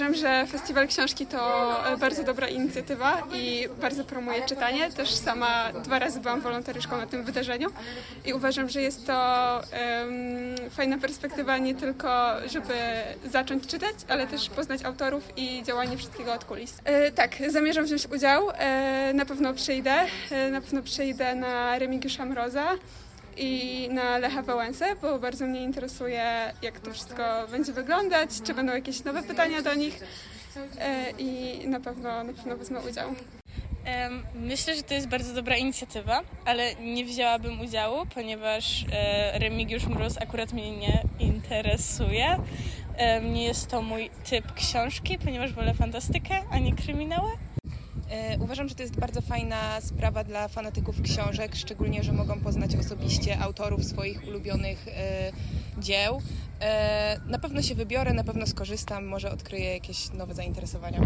Zapytaliśmy napotkanych studentów Uniwersytetu Opolskiego, co sądzą o takim festiwalu i oto, co usłyszeliśmy: